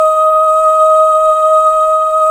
Index of /90_sSampleCDs/Club-50 - Foundations Roland/VOX_xFemale Ooz/VOX_xFm Ooz 2 S